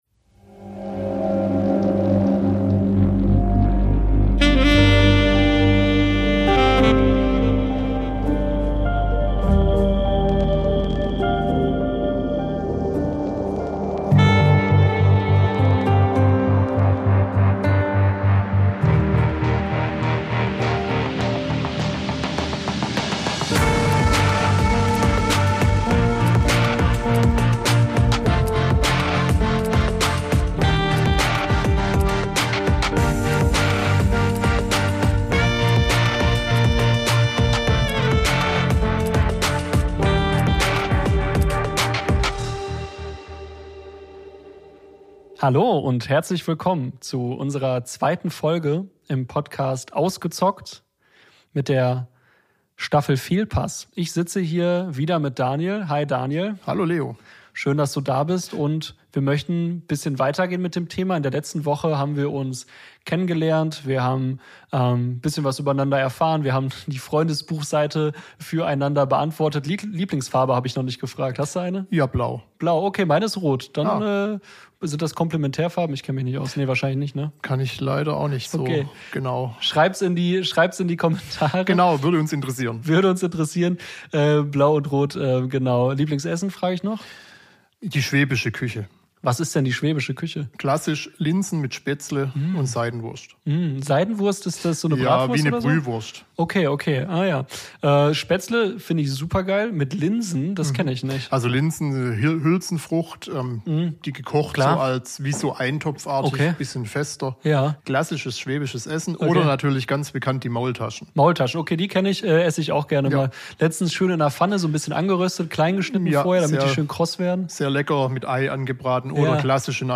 Folge 2: Die Spiele beginnen ~ Ausgezockt: Sucht ungefiltert - Betroffene im Gespräch Podcast